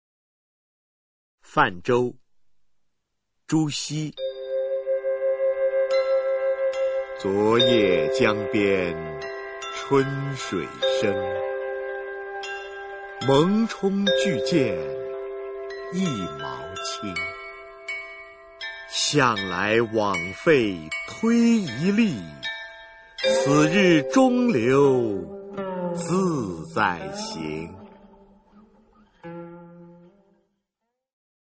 [宋代诗词诵读]朱熹-泛舟 宋词朗诵